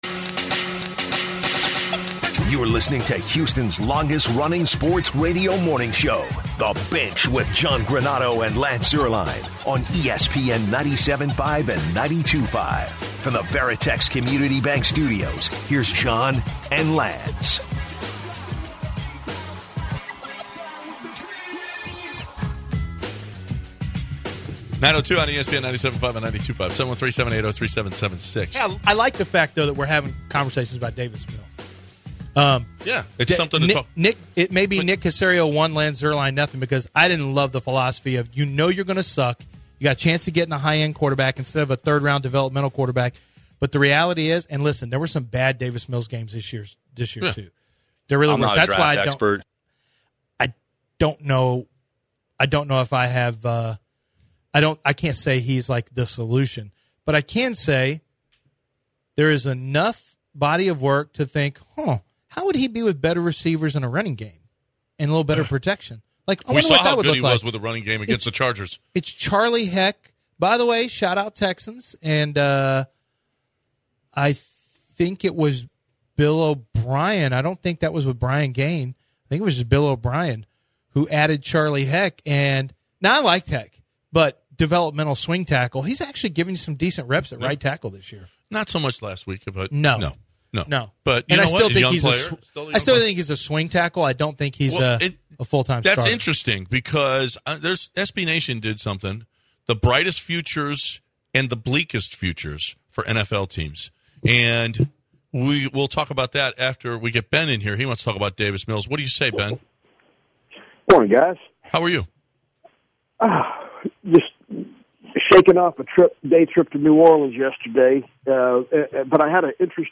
In the third and final hour the guys are joined by projected first round pick David Ojabo. David and the guys talk his past, his time in Nigeria and Scotland, his time at Michigan and what players he models his game after.